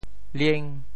lieng5.mp3